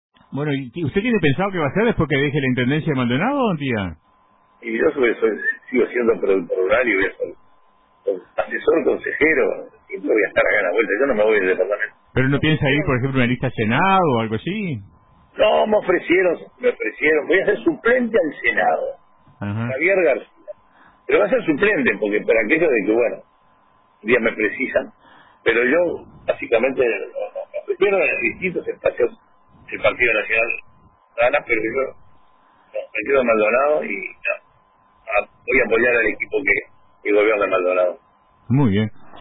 El intendente de Maldonado, el Ing. Agr. Enrique Antía, adelantó a RADIO RBC su futuro político: “Continúo siendo productor rural y asumiré el rol de asesor consejero.